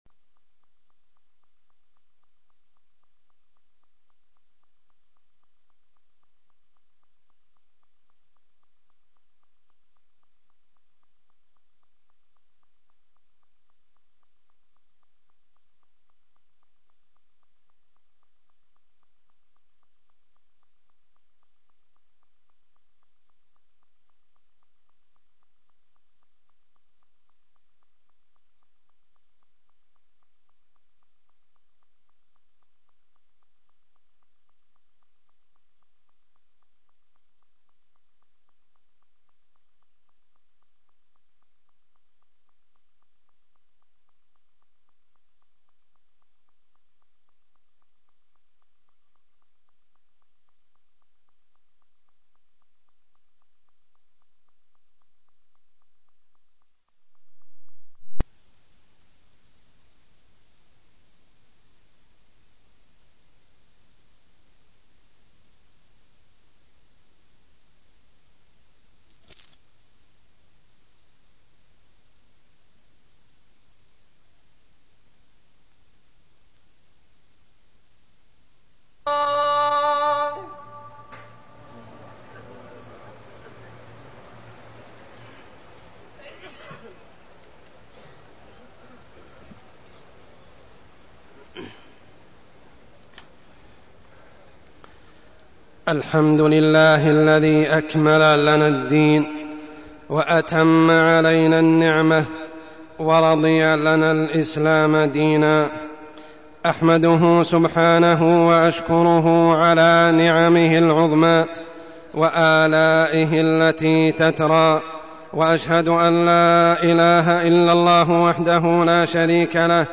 تاريخ النشر ٣ محرم ١٤١٨ هـ المكان: المسجد الحرام الشيخ: عمر السبيل عمر السبيل العام الهجري الجديد